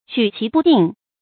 注音：ㄐㄨˇ ㄑㄧˊ ㄅㄨˋ ㄉㄧㄥˋ
舉棋不定的讀法